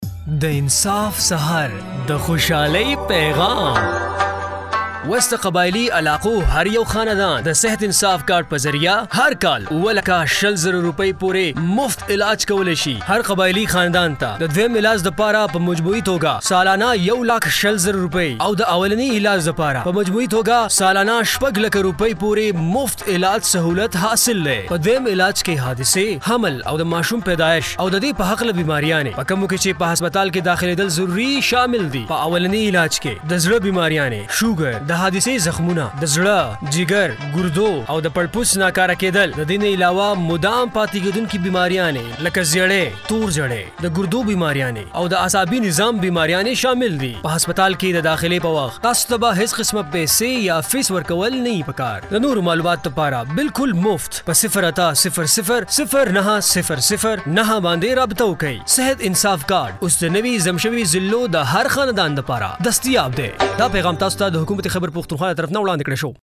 Sehat Insaf Card – Radio Spots